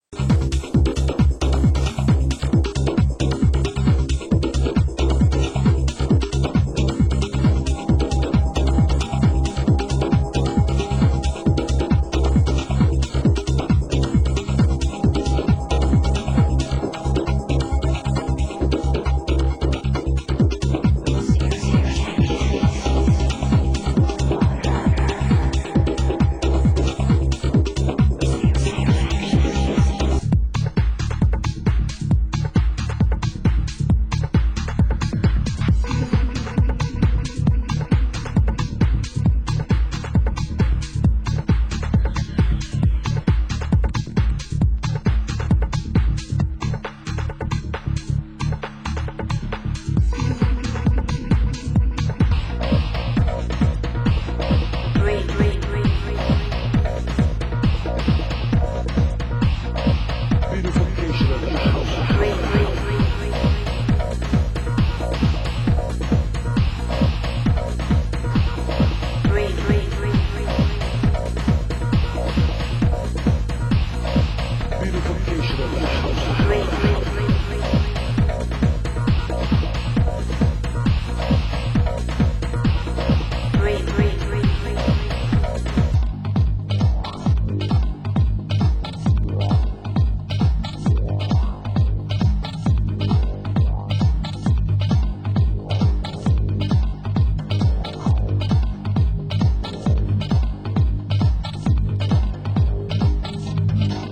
Genre: Tech House